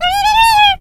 squeak_start_vo_01.ogg